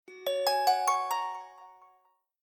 Звуки телефона Lenovo
Ice bell